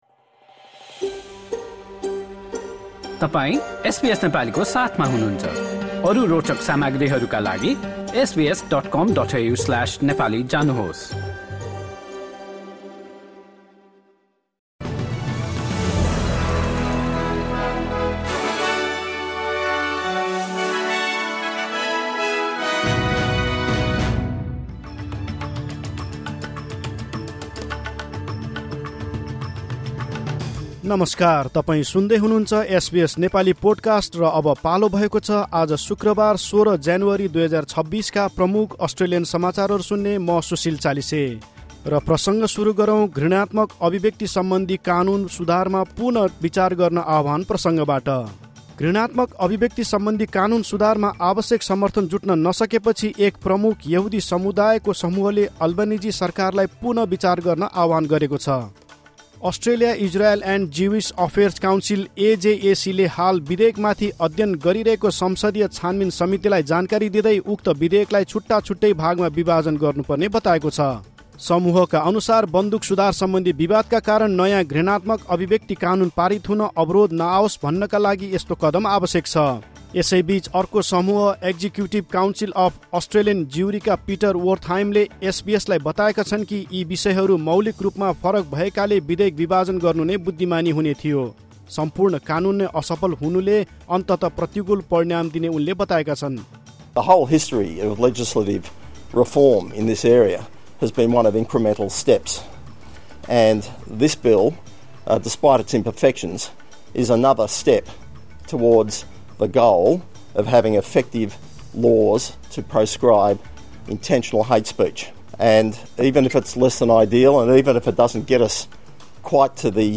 एसबीएस नेपाली प्रमुख अस्ट्रेलियन समाचार: शुक्रवार, १६ ज्यानुअरी २०२६